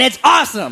Category: Comedians   Right: Both Personal and Commercial